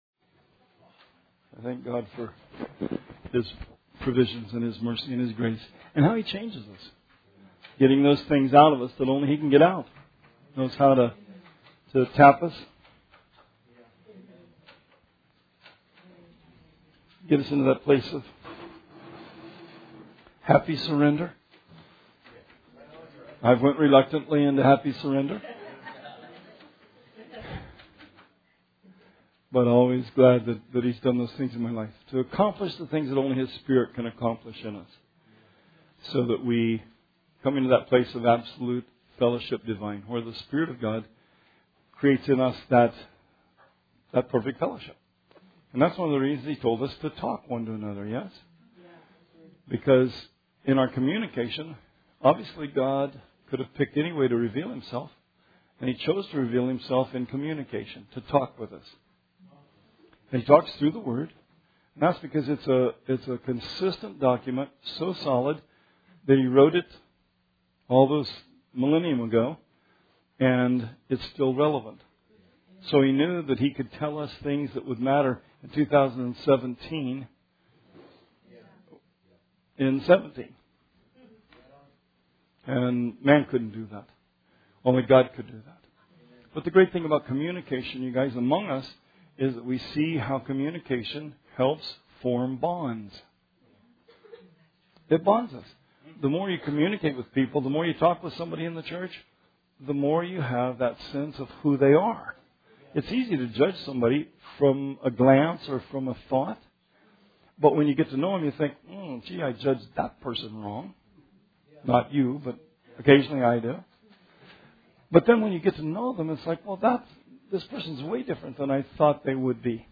Bible Study 10/4/17 – RR Archives